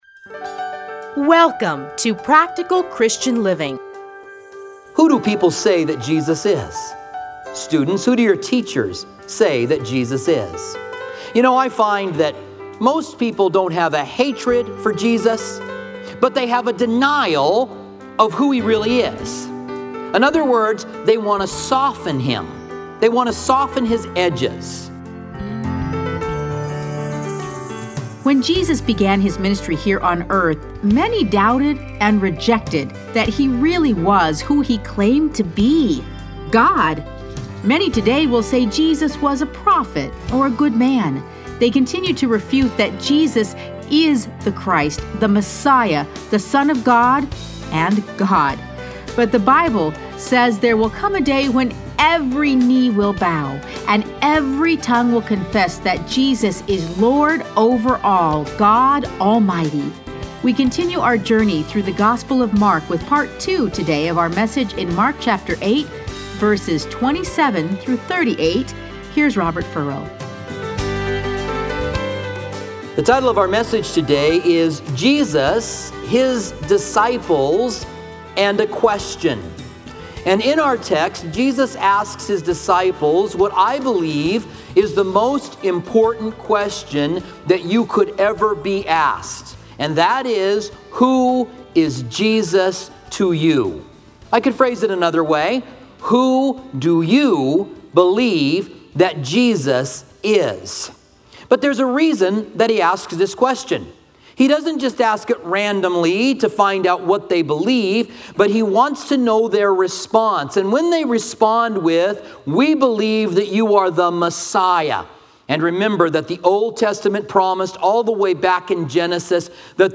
Listen to a teaching from Mark 8:27-38.